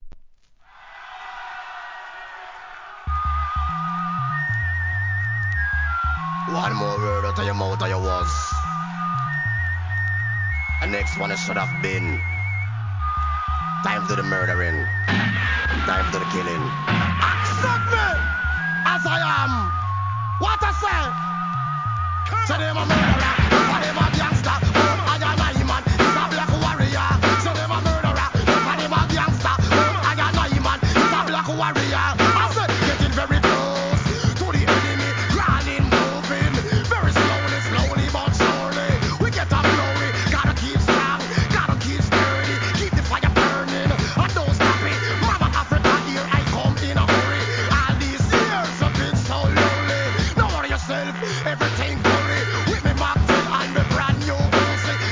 REGGAE
ラガHIP HOP